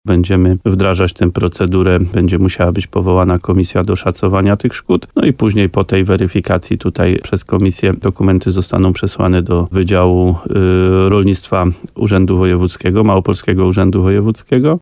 – Przymrozki miały uszkodzić uprawy lokalnie na terenie niektórych miejscowości – mówił wójt Adam Wolak w programie Słowo za Słowo na antenie RDN Nowy Sącz.